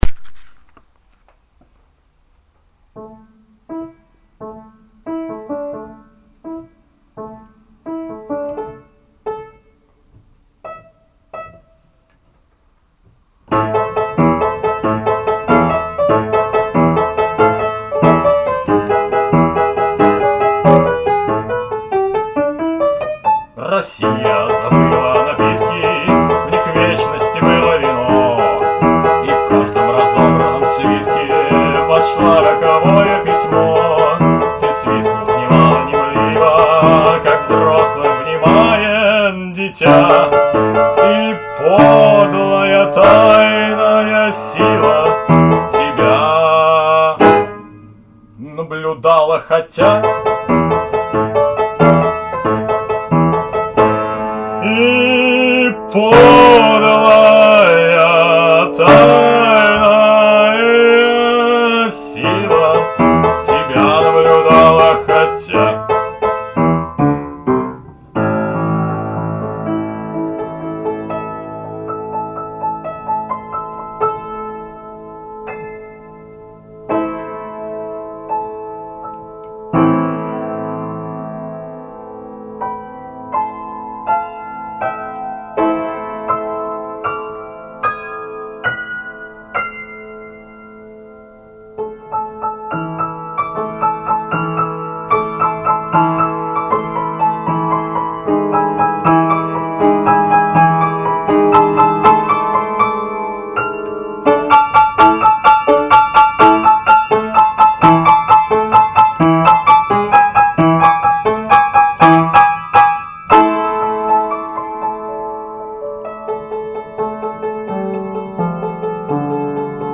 ОСТОРОЖНО! ДОМРА!